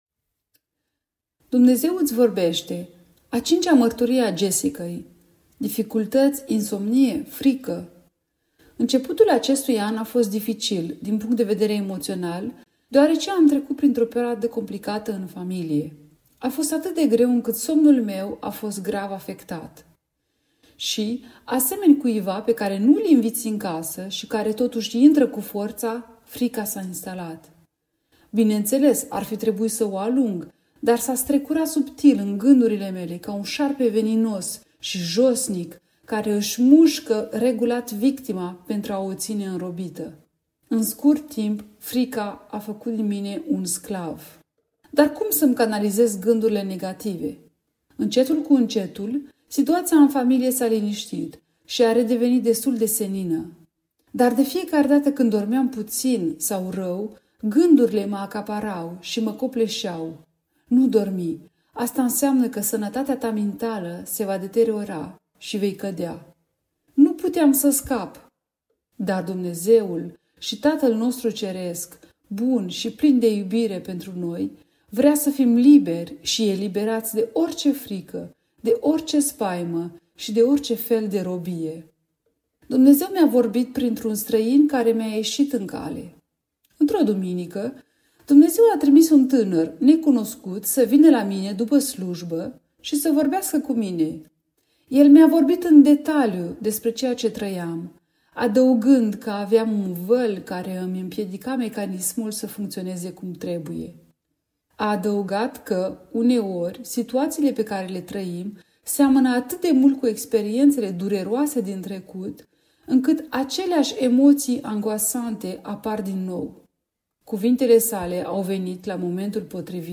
Dumnezeu-iti-vorbeste_ralenti.mp3